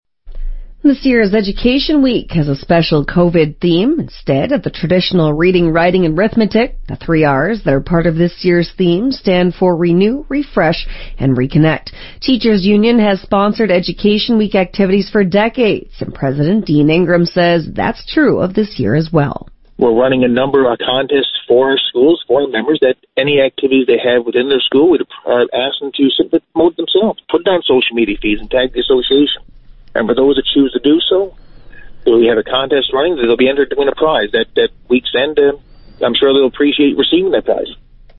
Media Interview - VOCM 8am News Jan. 19, 2021